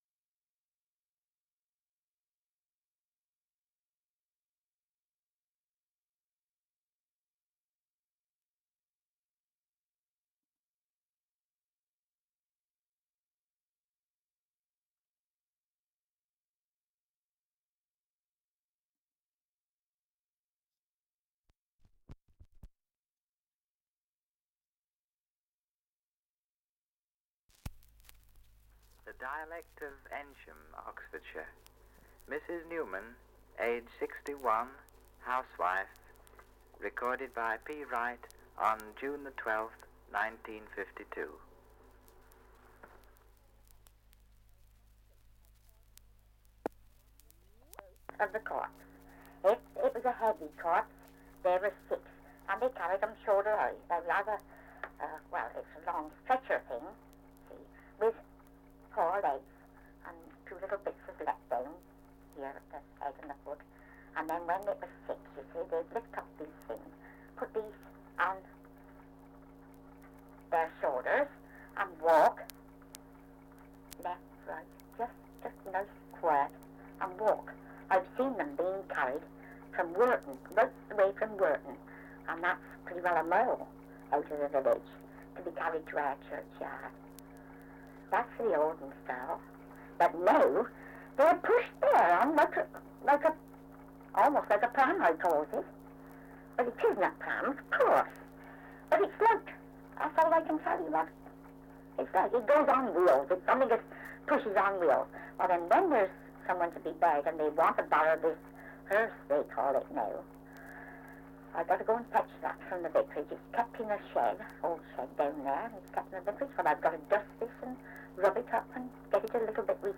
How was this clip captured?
Survey of English Dialects recording in Eynsham, Oxfordshire 78 r.p.m., cellulose nitrate on aluminium